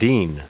Prononciation du mot dean en anglais (fichier audio)
Prononciation du mot : dean